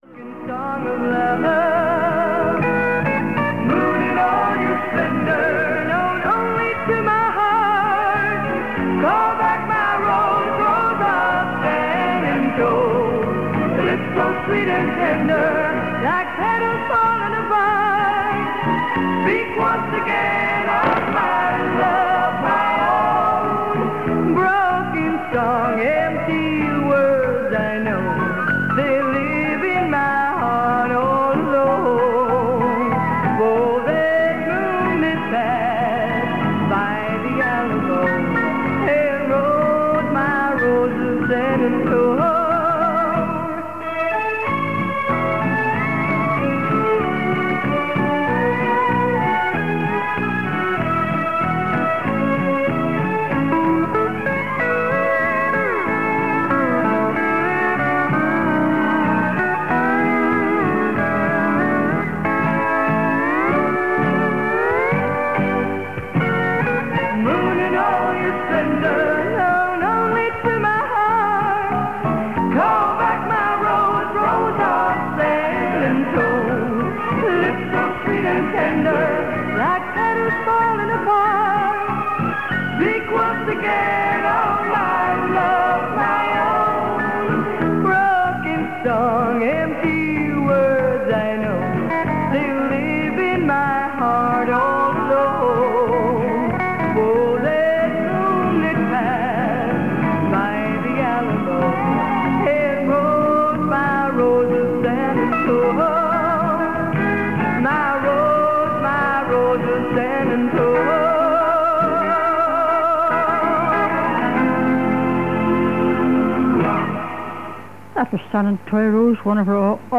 The station was among a handful of pirates to defy the new broadcasting laws and continue broadcasting in 1989. Radio North returned on tape on 5th January 1989 and resumed live programmes on 6th January on 97.9 FM and 846 kHz AM, putting out its usually good signal into Britain, according to the Anoraks UK Weekly Report.